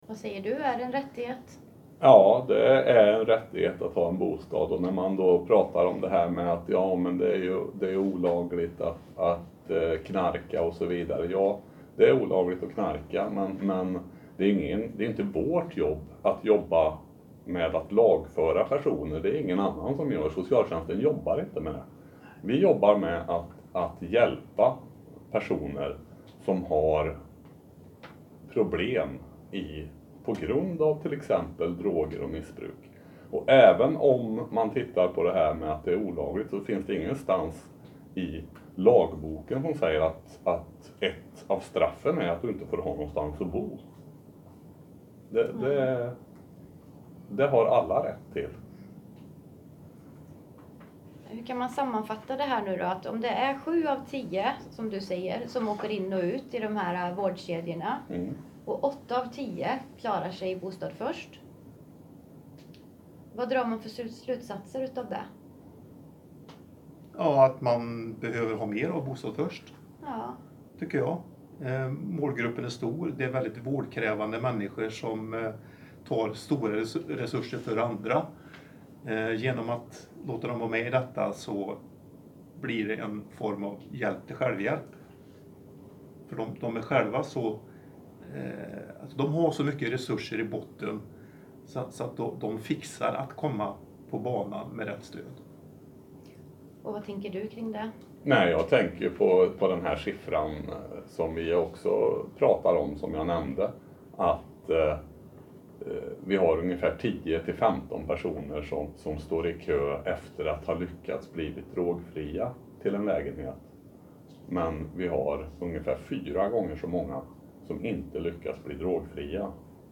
Ljudklippen nedan har vi spelat in efter presentationen vid stormötet.
Karlstad-del-5-Samtal-Bostad-först-karlstad.mp3